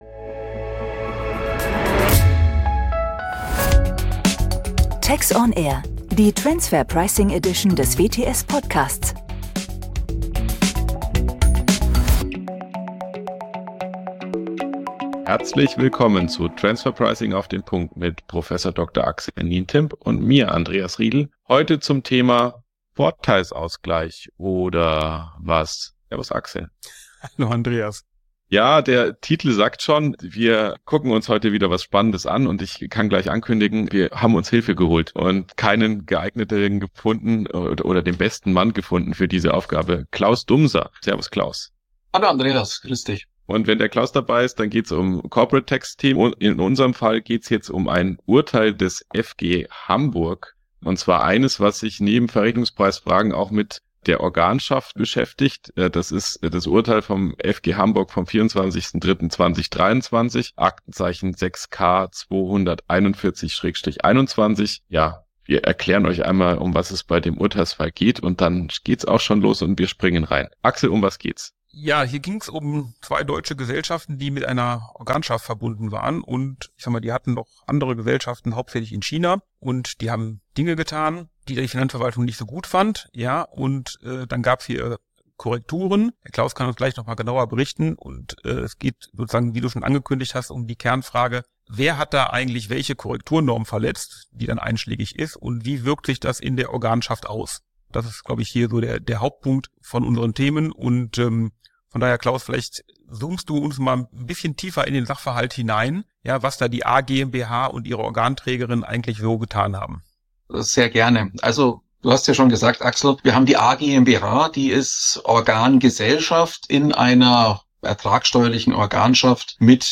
Die Finanzverwaltung wollte hier Verrechnungspreiskorrekturen vornehmen; die Diskussion der drei Experten dreht sich um die Frage: Wer hat welche Korrekturnorm verletzt und wie wirkt sich das in der Organschaft aus?